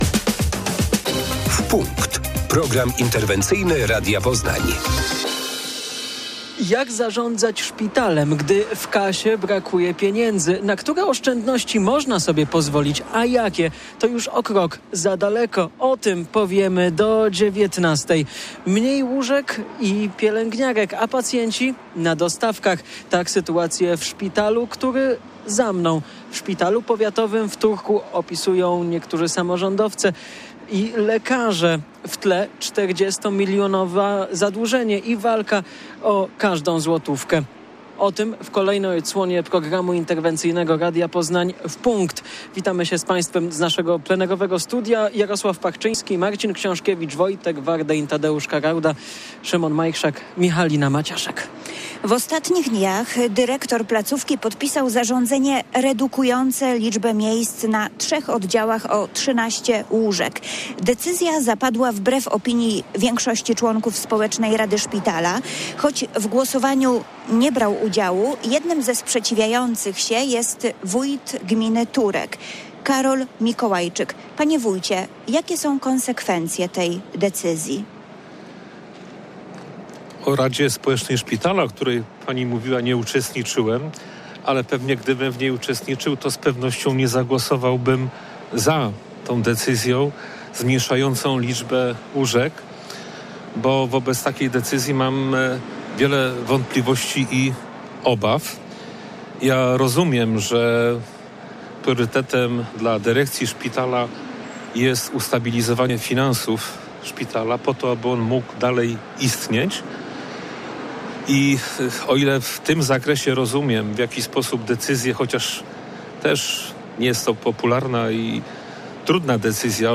Podczas audycji „W Punkt” samorządowcy mówili o skutkach zmniejszenia liczby łóżek na trzech oddziałach, w tym na ortopedii.